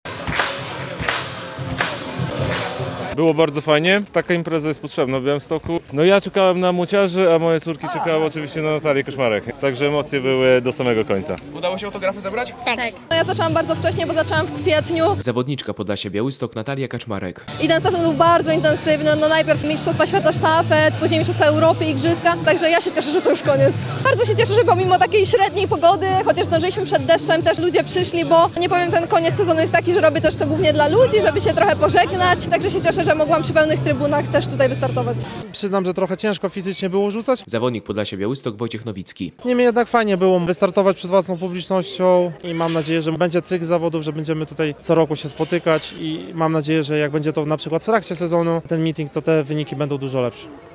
Mityng Ambasadorów - relacja